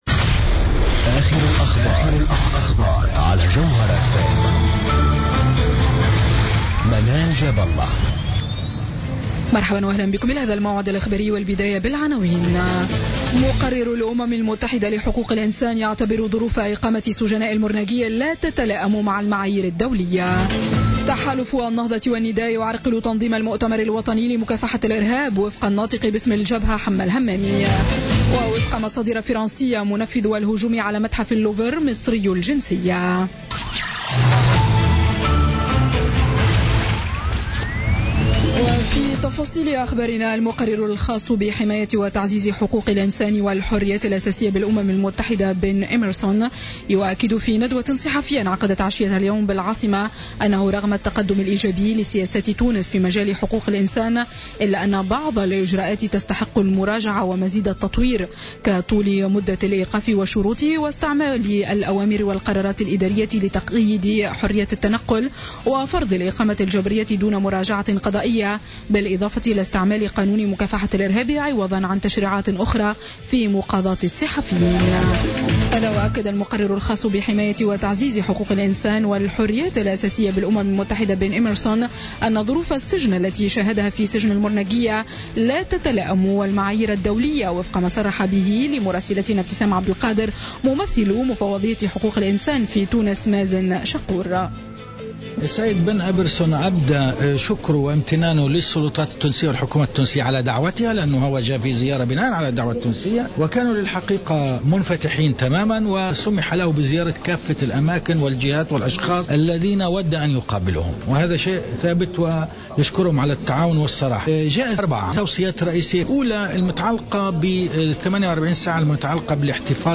نشرة أخبار السابعة مساء ليوم الجمعة 3 فيفري 2017